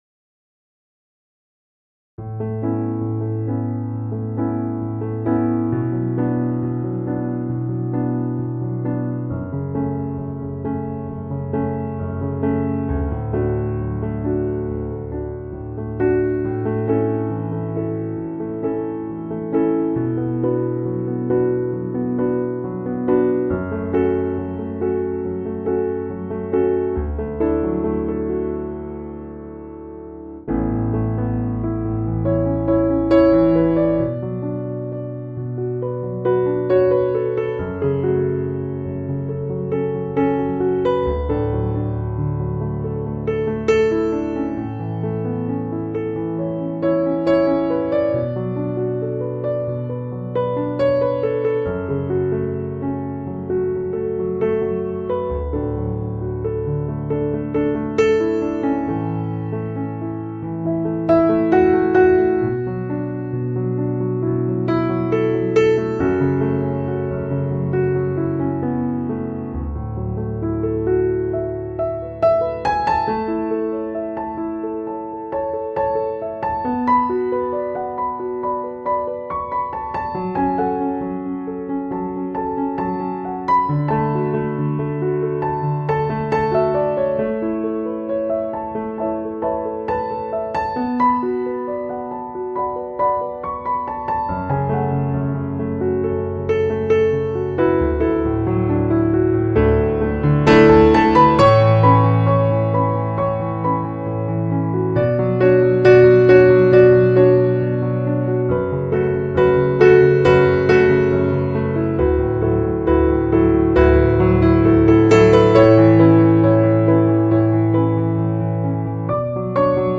Жанр: Piano, Instrumental